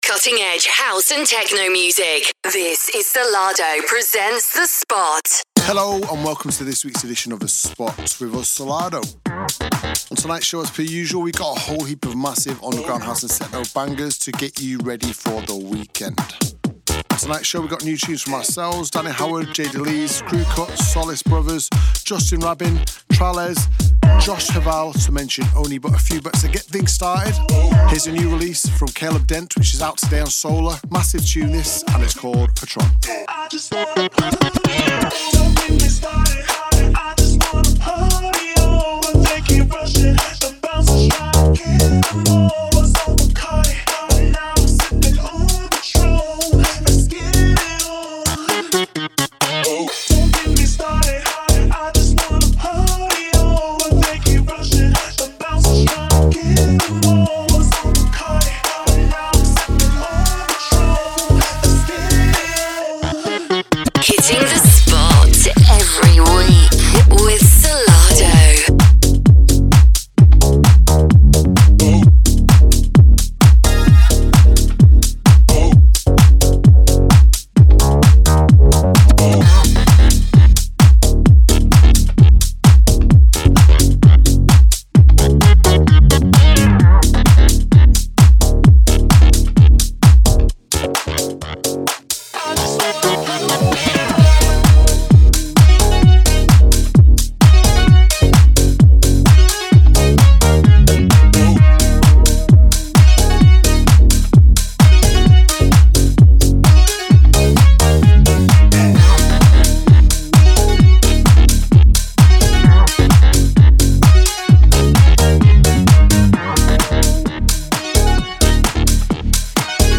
with exclusive live mixes and guest appearances.